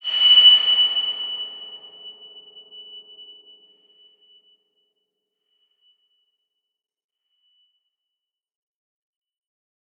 X_BasicBells-F#5-ff.wav